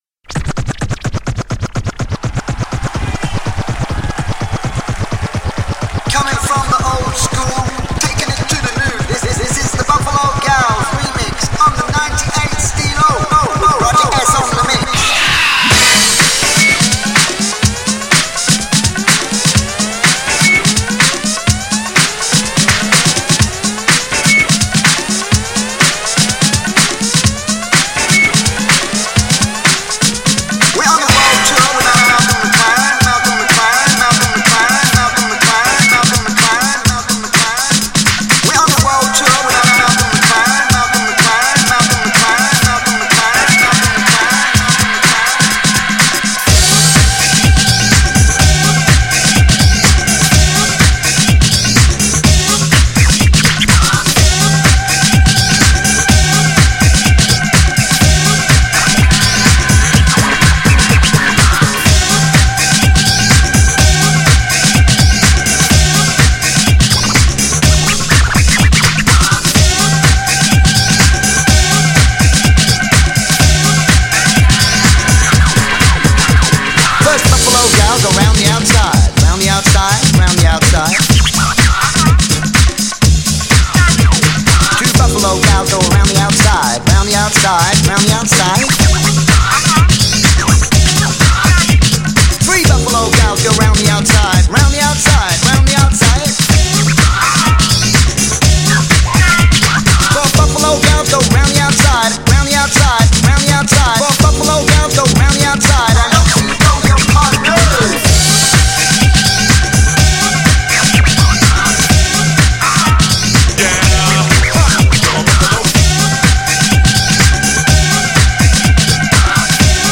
より一層HIP HOP色がパワーアップ!!
GENRE Hip Hop
BPM 86〜90BPM